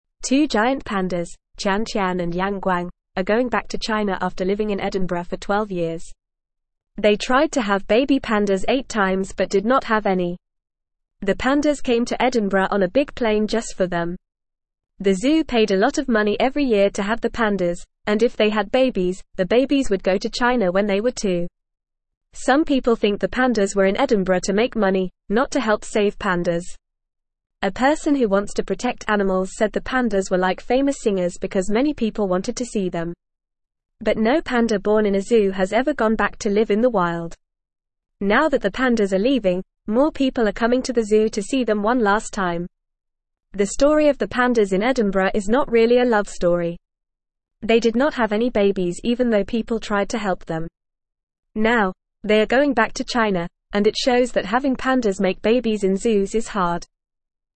Fast
English-Newsroom-Lower-Intermediate-FAST-Reading-Big-Bears-Tian-Tian-and-Yang-Guang-Go-Home.mp3